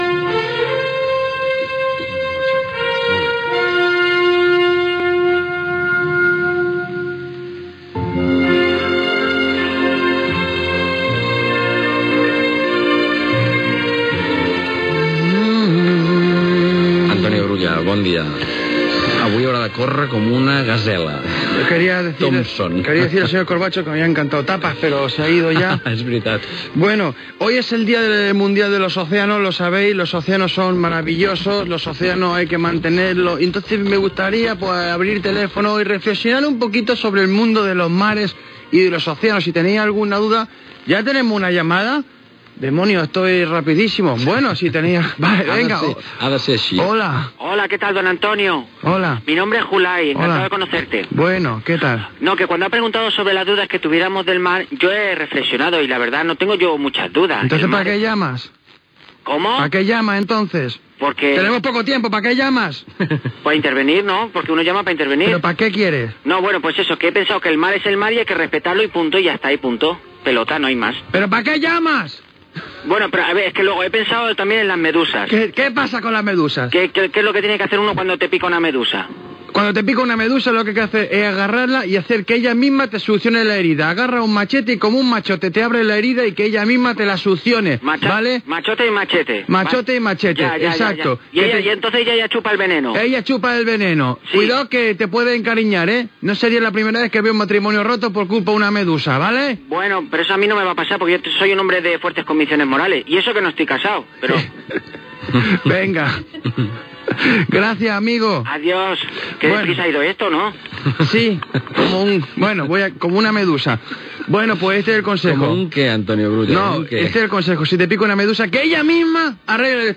Secció humorística de "Los especialistas scundarios".
Info-entreteniment